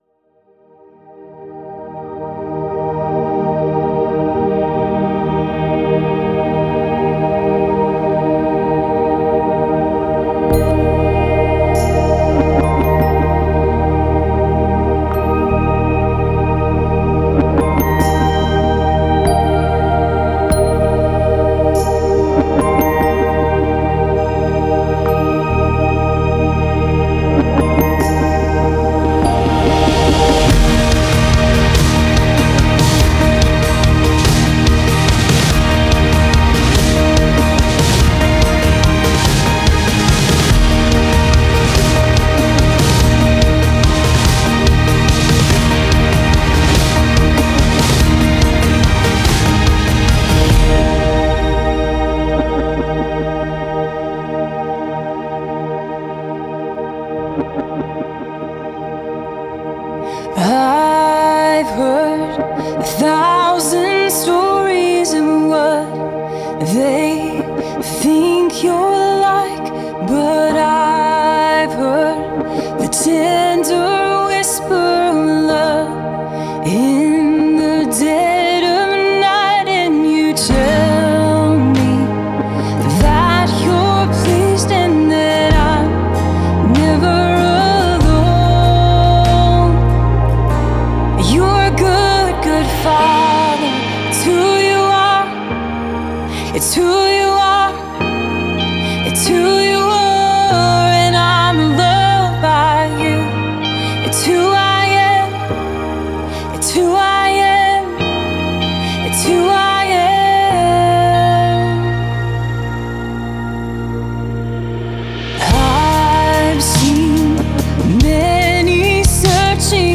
Keys
Bass
Drums
Guitars
Recorded in Nashville, TN